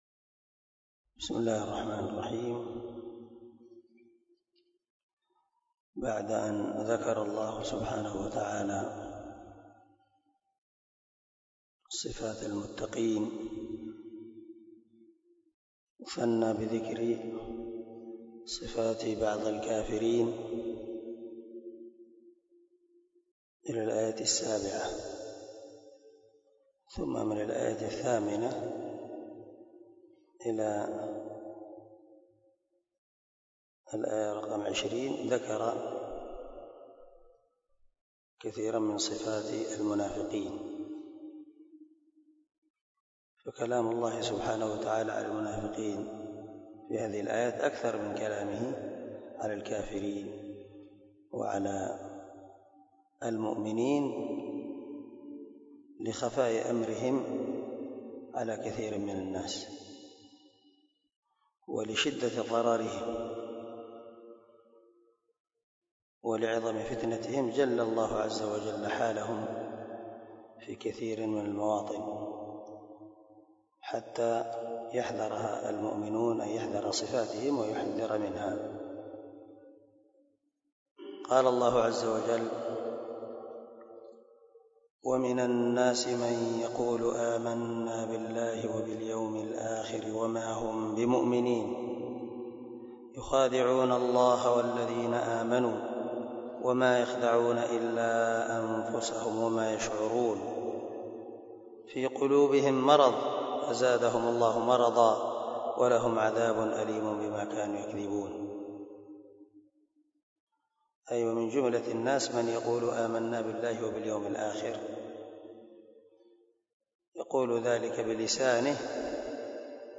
014الدرس 4 تفسير آية ( 8 - 12 ) من سورة البقرة من تفسير القران الكريم مع قراءة لتفسير السعدي
دار الحديث- المَحاوِلة- الصبيح